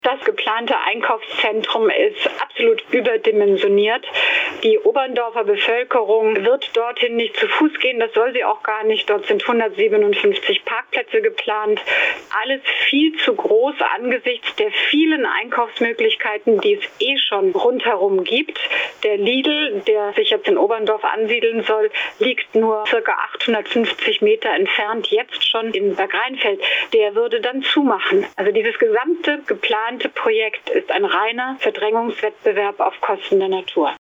Seit dem 10.03. sammelt das Bürgerbegehren „Natur statt Beton“ Unterschriften, um den Bau des Einkaufszentrums in Oberndorf, Schweinfurt zu verhindern. Zusammen mit anderen Schweinfurterinnen, Organisationen und Parteien, kämpft Stadträtin Ulrike Schneider gegen die Flächenversiegelung. Frau Schneider, warum halten Sie den Bau des Einkaufszentrums für unnötig?